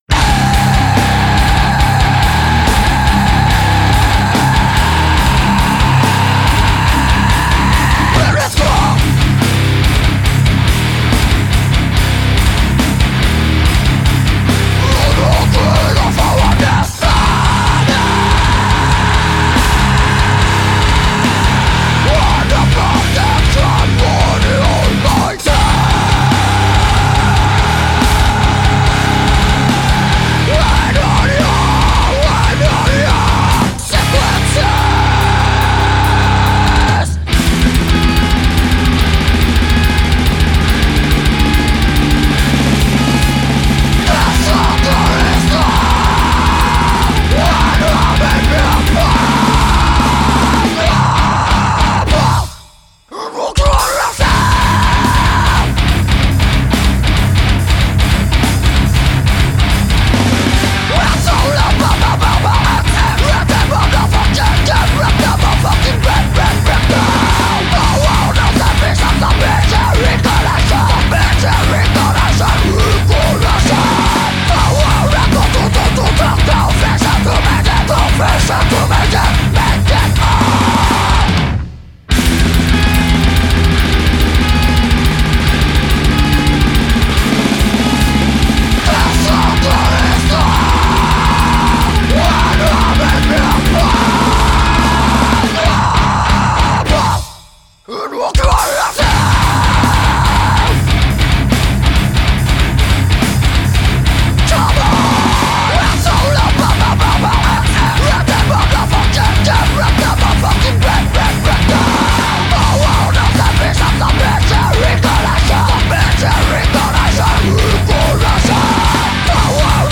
Il y a plusieurs façons d’envisager le grind.
violence des riffs qui arrivent progressivement